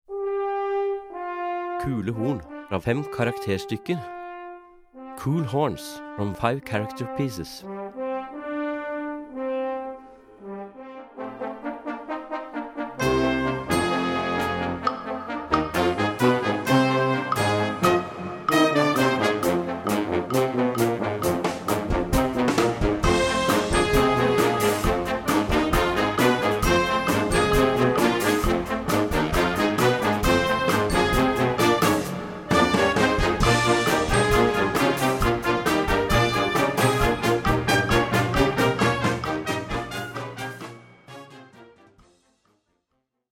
1:38 Minuten Besetzung: Blasorchester Tonprobe